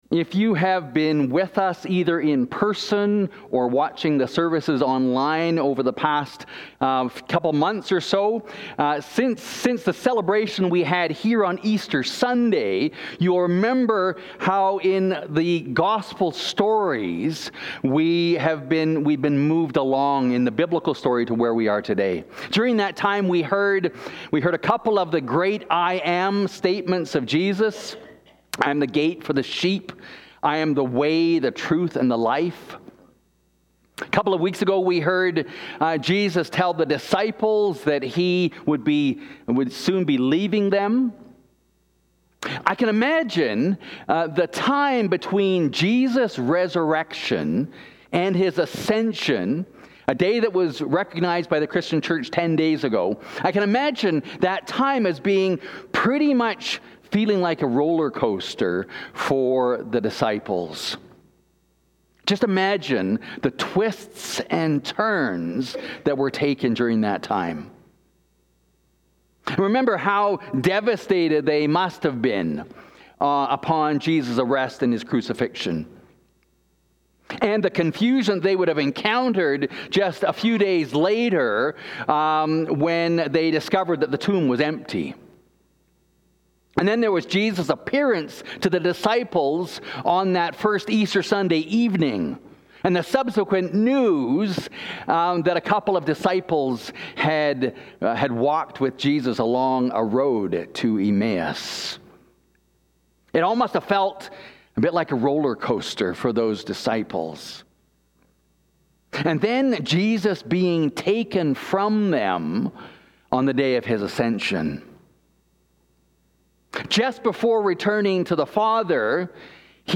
Acts 2:1-21 Please click here to view the sermon questions.